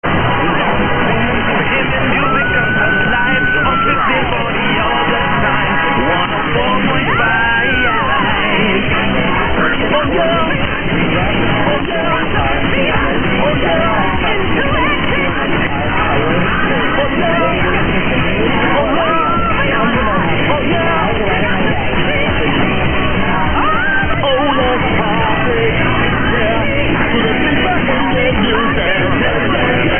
Somewhat scratchy recording from here with calypso-style jingle mentioning 104.5, the FM.
Thanks to a mostly-water route, reception is fairly common.